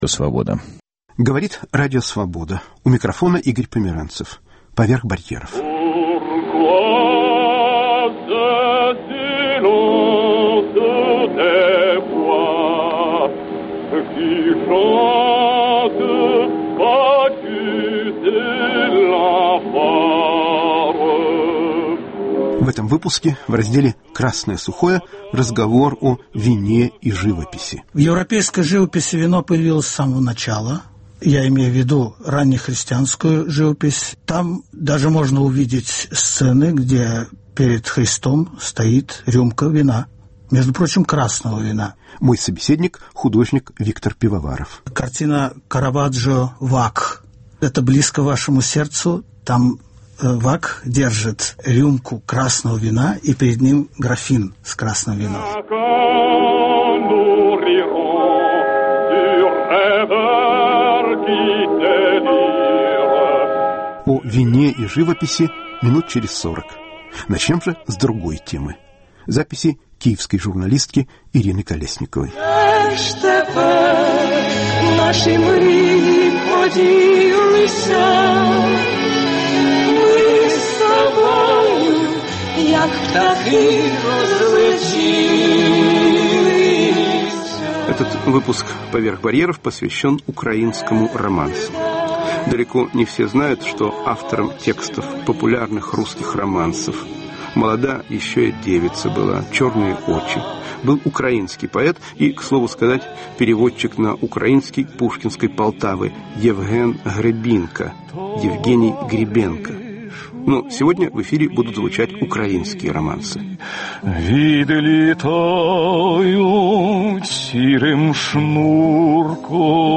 Рассказывают украинские историки культуры, музыканты, режиссёры*** "Красное сухое". Живопись и вино: беседа с художником Виктором Пивоваровым